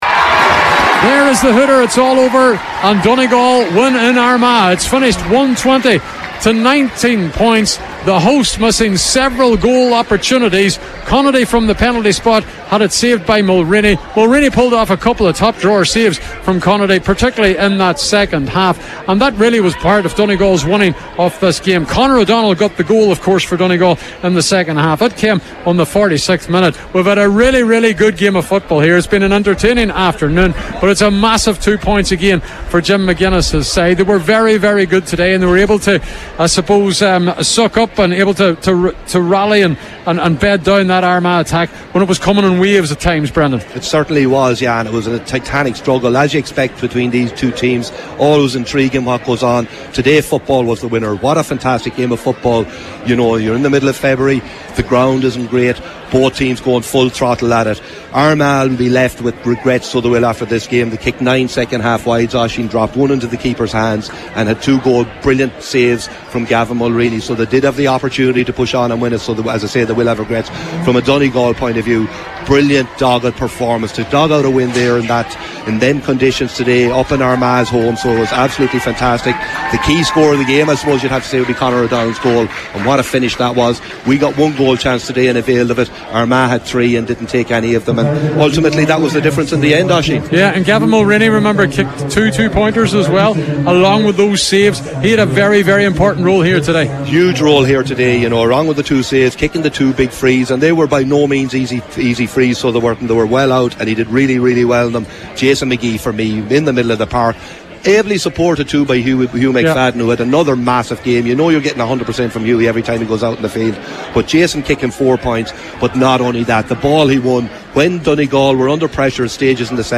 Donegal go four-from-four with hard-fought win in Armagh – Post-Match Reaction